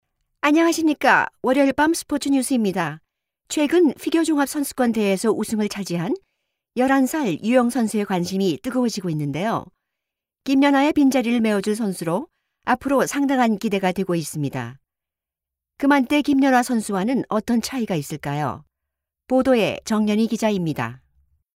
Korean Voiceovers
Voice samples in Korean and English can be heard by clicking the links below:
sports-news.mp3